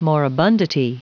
Prononciation du mot moribundity en anglais (fichier audio)
Prononciation du mot : moribundity